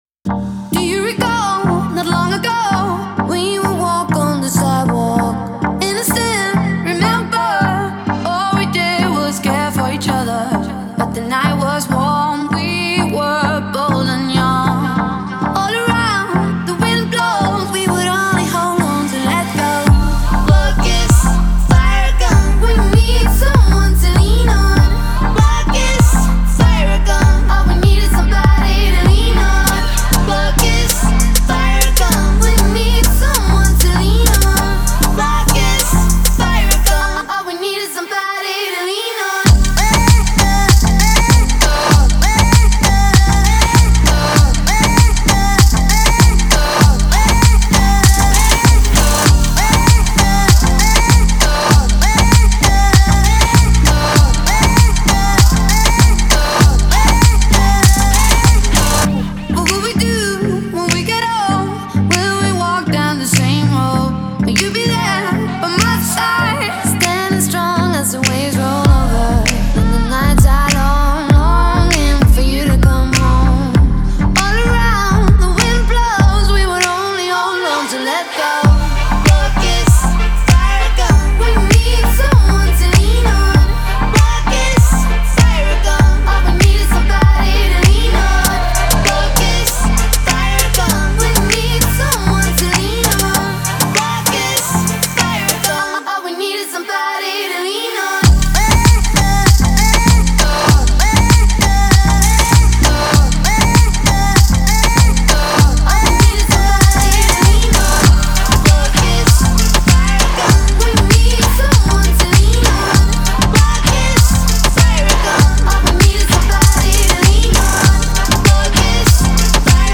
BPM98